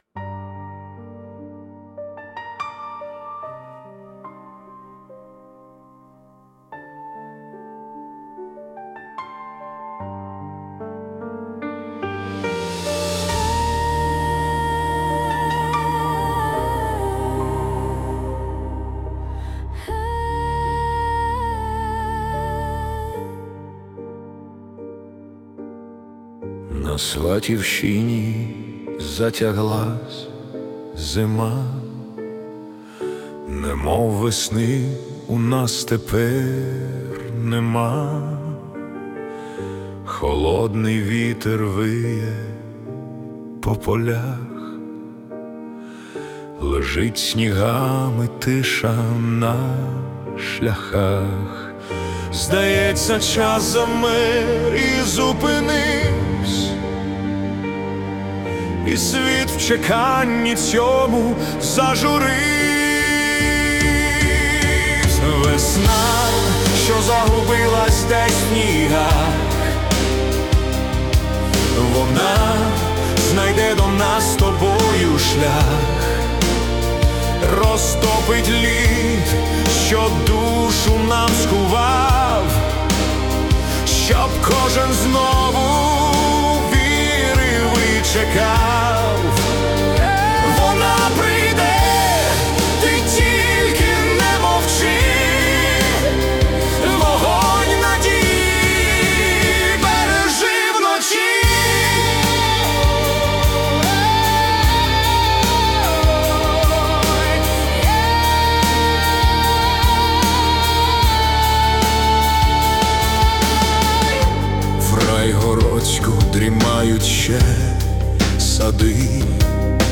Country Rock / Epic Ballad